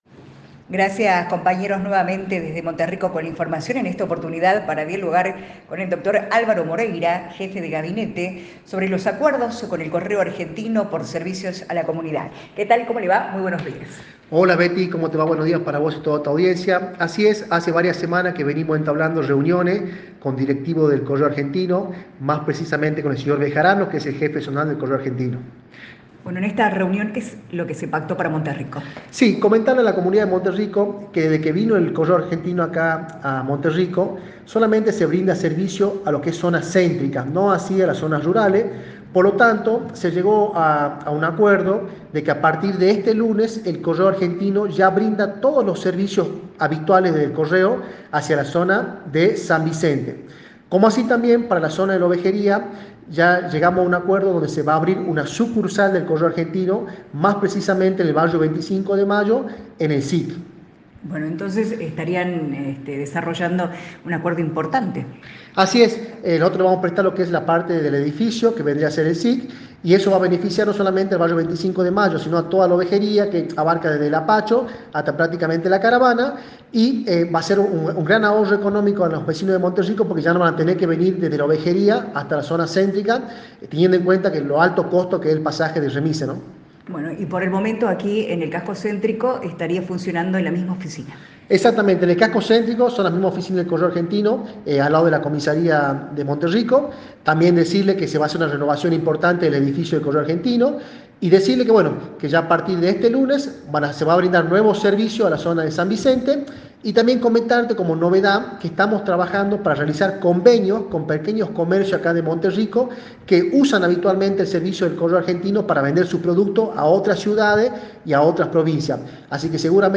(MONTERRICO),- El Dr. Alvaro Moreira, jefe de gabinete, se refiere al acuerdos con el correo Argentino por servicios a la comunidad, extensión del servicio en la zona rural y apertura de una sucursal en el CIC del barrio 25 de mayo.